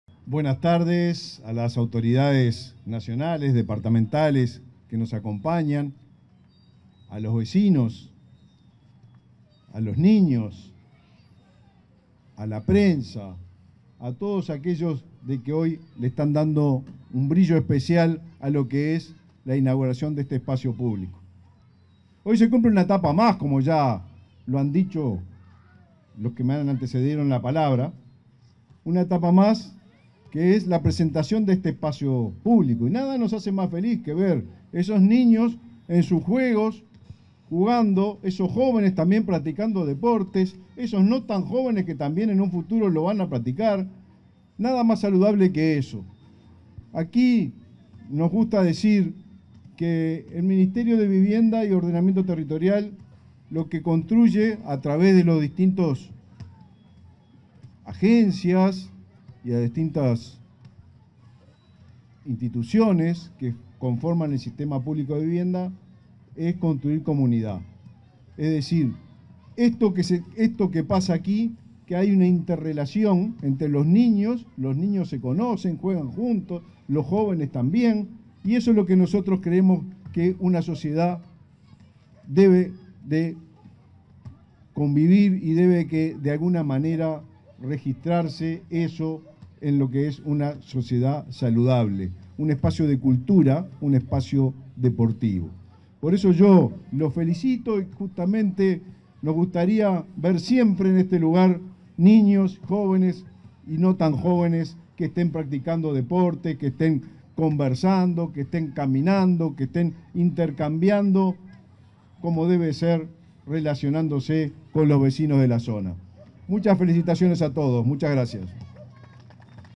Palabras del ministro de Vivienda y Ordenamiento Territorial, Raúl Lozano
Con motivo de la inauguración del espacio público en el barrio Cauceglia de Montevideo, en el marco del plan Avanzar, este 16 de julio, el ministro de